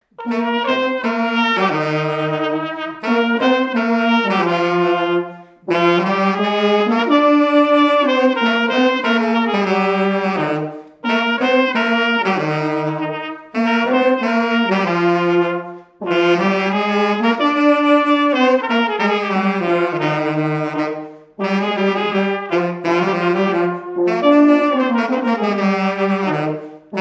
Champagné-les-Marais
circonstance : fiançaille, noce
Pièce musicale inédite